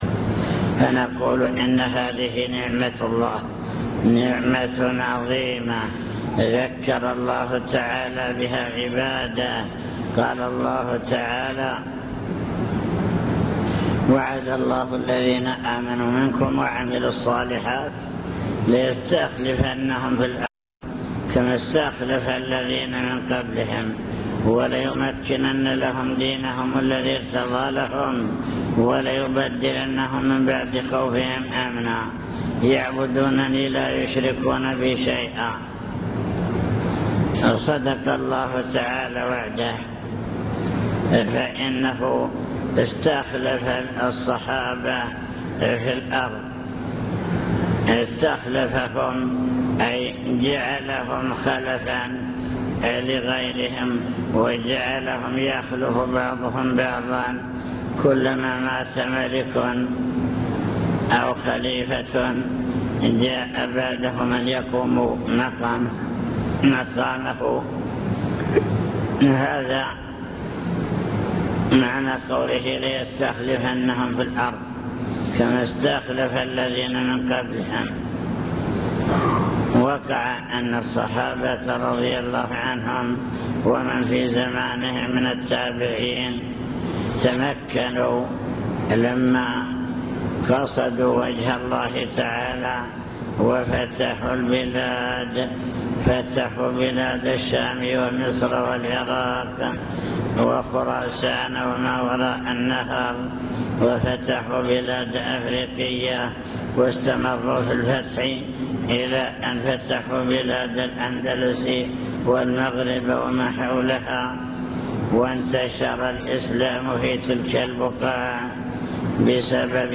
المكتبة الصوتية  تسجيلات - لقاءات  كلمة في مسجد نعم الله لا تحصى